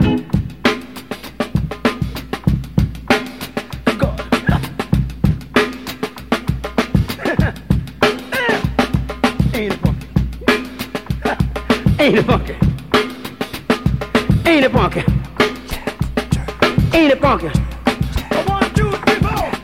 • 98 Bpm Modern Drum Loop Sample B Key.wav
Free drum beat - kick tuned to the B note. Loudest frequency: 1178Hz
98-bpm-modern-drum-loop-sample-b-key-44P.wav